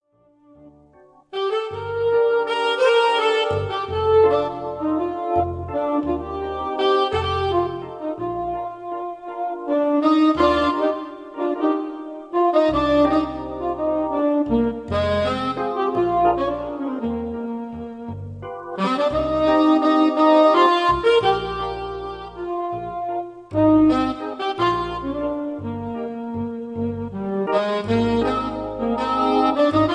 lilting waltz for slow-step dancing